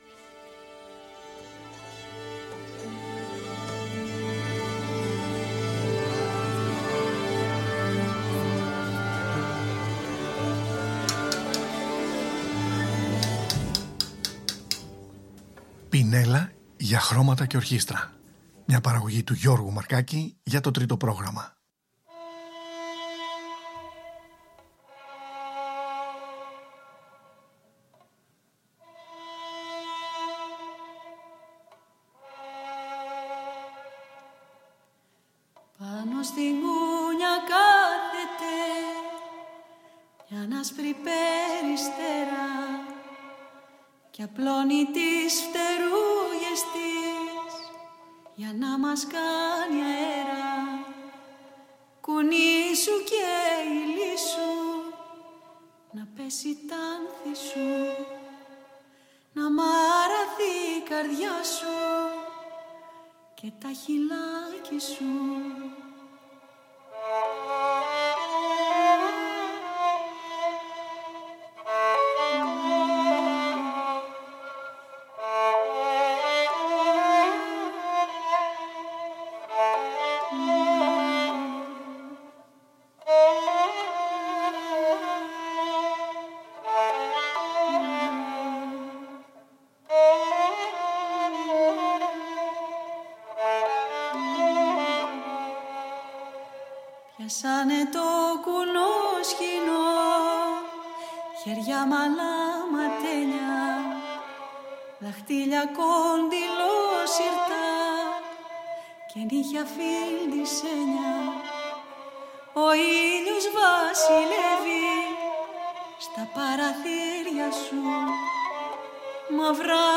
Αυτό είναι το δεύτερο πρόγραμμα / ενότητα από την πρόσκληση/ανάθεση που έλαβα από το Β’ Πρόγραμμα της Δημόσιας Ραδιοφωνίας της Σουηδίας να παρουσιάσω σύγχρονα Μεσογειακά ακούσματα με βάση τις σύγχρονες λαϊκές / δημοτικές παραδόσεις.